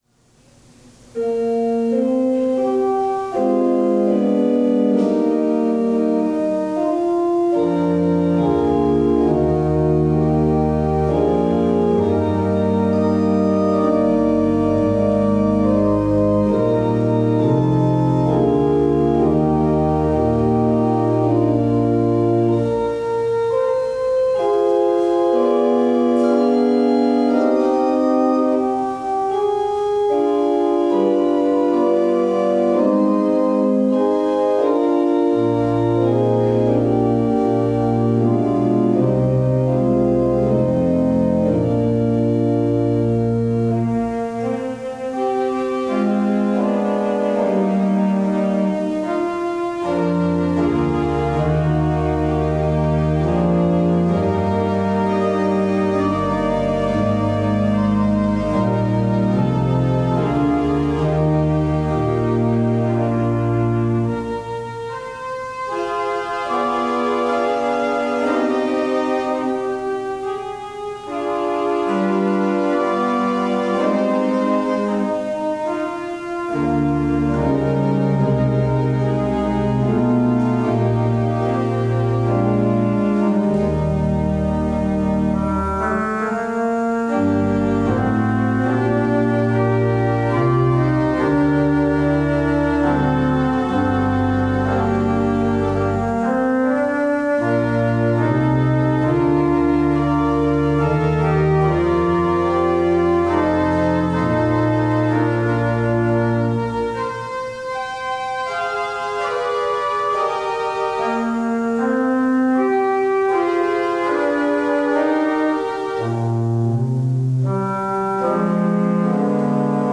Service of Worship
Prelude